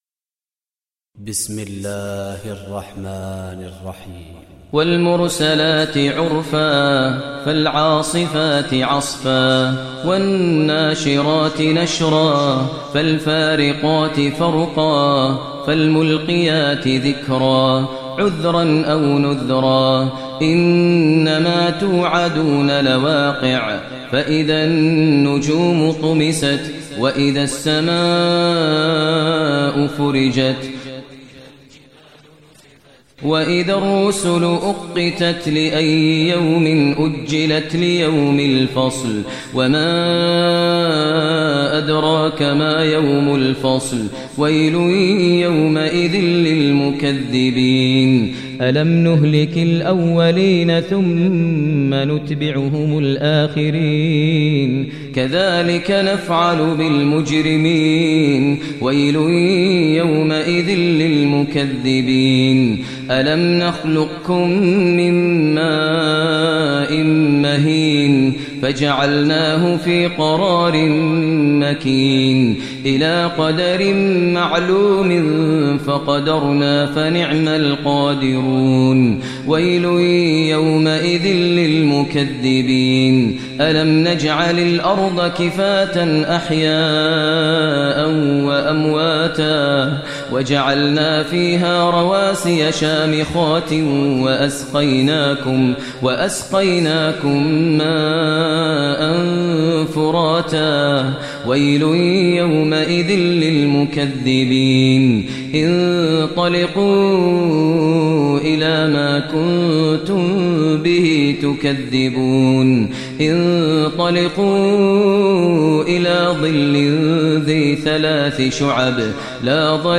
Surah Mursalat Recitation by Sheikh Maher Mueaqly
Surah Mursalat, listen online mp3 tilawat / recitation in Arabic recited by Imam e Kaaba Sheikh Maher al Mueaqly.